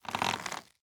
Minecraft Version Minecraft Version snapshot Latest Release | Latest Snapshot snapshot / assets / minecraft / sounds / item / crossbow / loading_start.ogg Compare With Compare With Latest Release | Latest Snapshot
loading_start.ogg